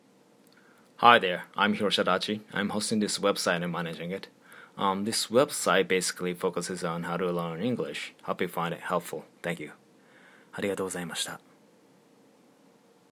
【実際の英会話の実力】